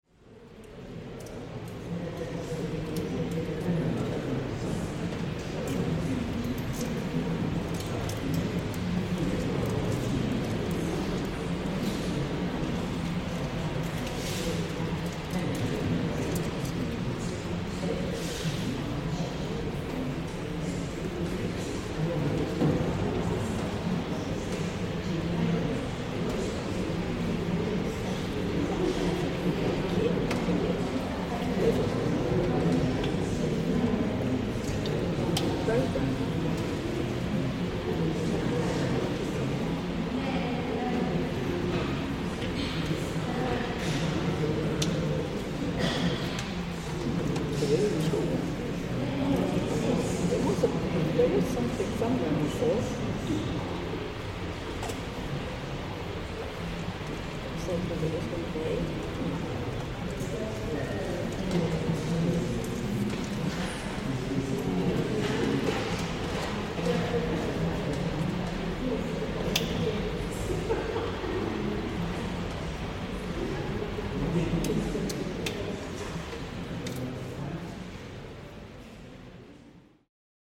Today, the church echoes with the sighs of anticipation as people gather for a baroque concert beneath its grand arches. Amid the summer heat, the faint buzz of fans offers a quiet counterpoint to the sacred stillness, blending with the whispers and soft steps of concertgoers. As the space fills with the energy of the present moment, the church’s rich historical legacy lingers in the background, awaiting the first notes of the performance to lift the air.